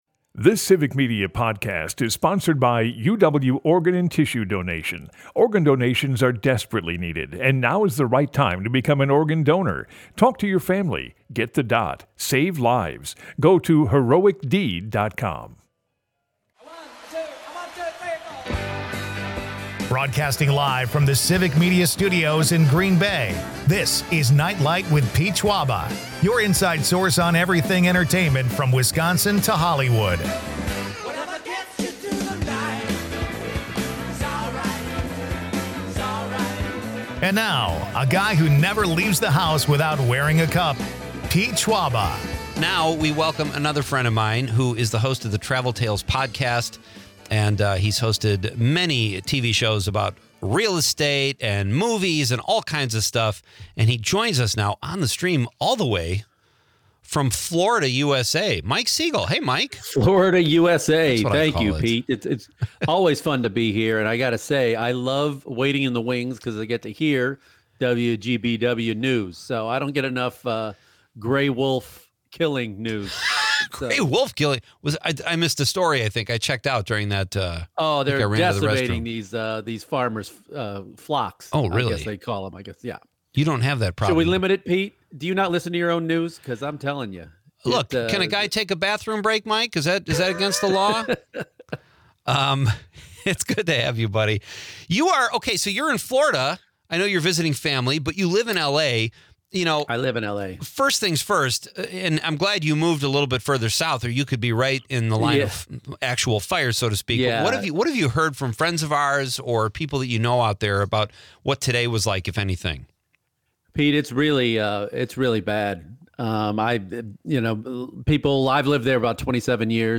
airs Monday through Friday from 6-8 pm across Wisconsin